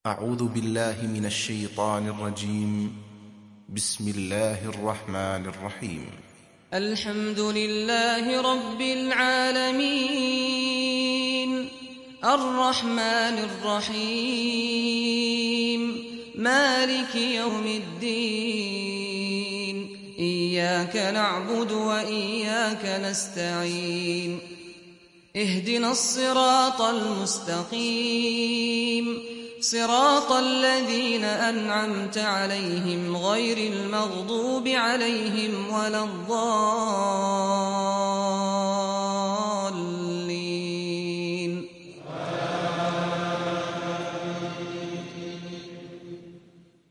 تحميل سورة الفاتحة mp3 بصوت سعد الغامدي برواية حفص عن عاصم, تحميل استماع القرآن الكريم على الجوال mp3 كاملا بروابط مباشرة وسريعة